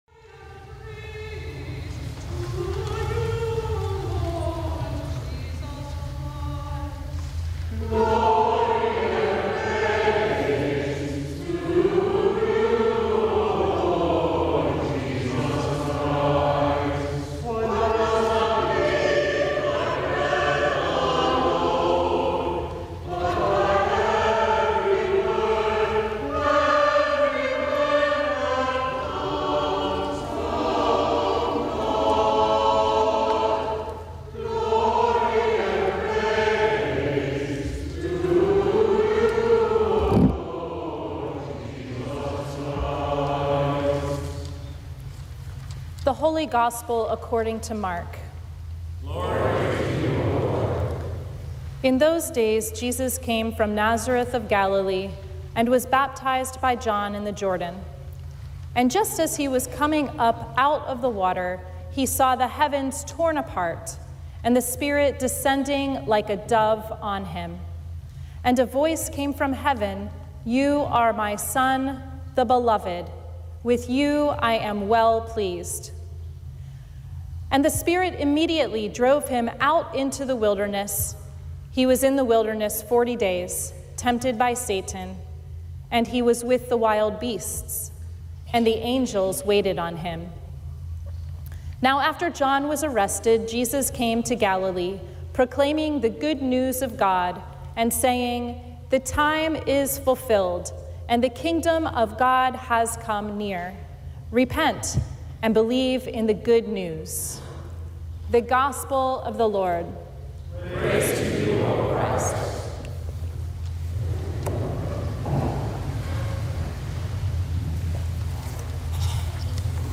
Sermon from the First Sunday in Lent